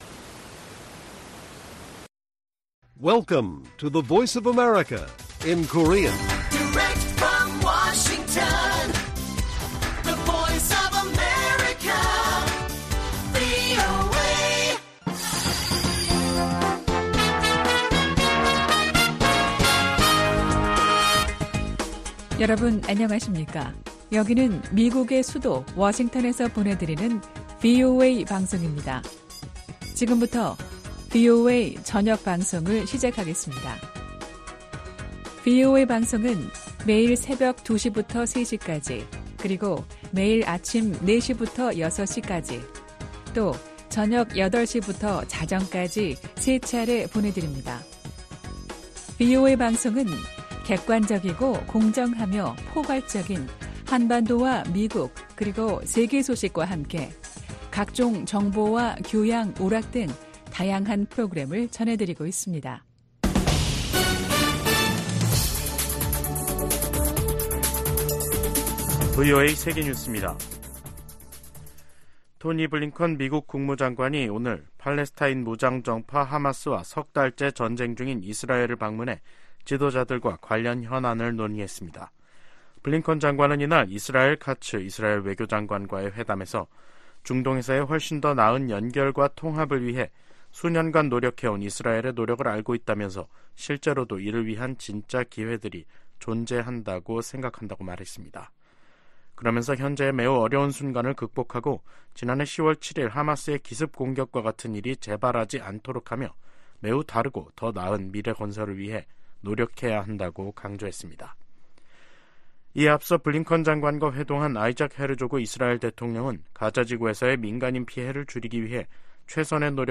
VOA 한국어 간판 뉴스 프로그램 '뉴스 투데이', 2024년 1월 9일 1부 방송입니다. 미국이 북한에 도발 자제와 외교적 해결을 촉구하는 한편 한국에 확고한 방위 공약을 거듭 확인했습니다. 북한이 포격 도발을 한 것은 미한일 3국 협력 불만 표출과 총선을 앞둔 한국을 혼란시키려는 것으로 미 전문가들은 분석했습니다. 북한이 러시아에 첨단 단거리 미사일(SRBM)까지 넘긴 것으로 알려지면서 군사협력이 상당히 높은 수준에서 이뤄질 가능성이 제기되고 있습니다.